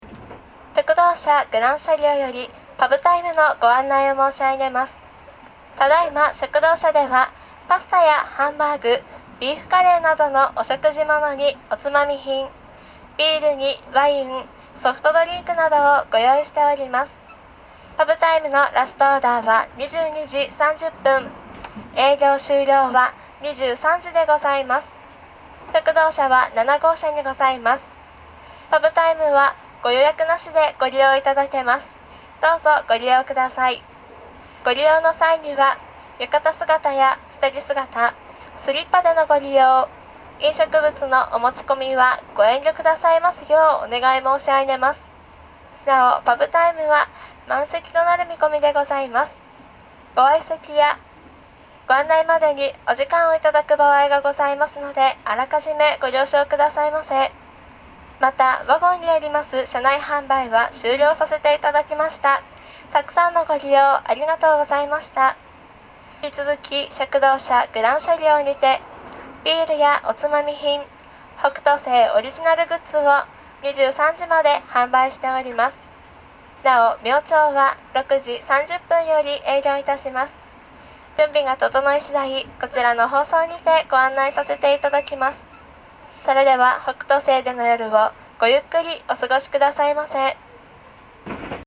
やがて21：03頃食堂車から
案内がありました（再生時間1分31秒）
020-pubtimeannai.mp3